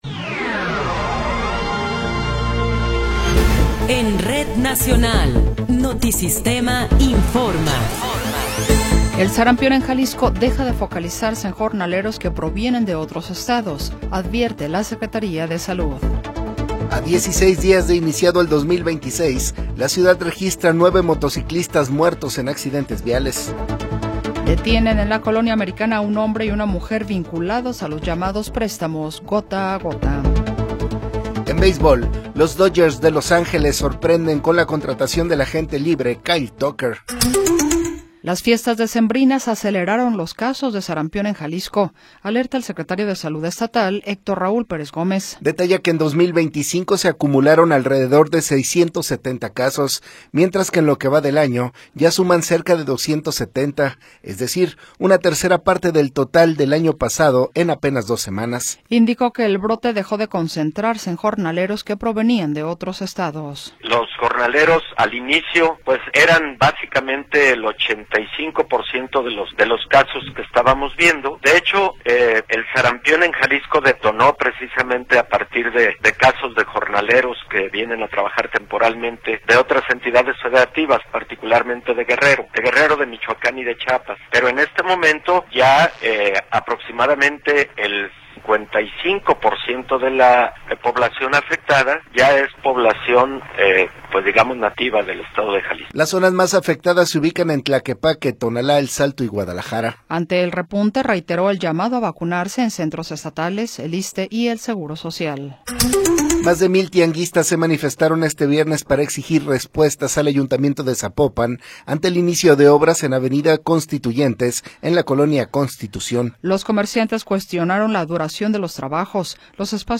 Noticiero 20 hrs. – 16 de Enero de 2026
Resumen informativo Notisistema, la mejor y más completa información cada hora en la hora.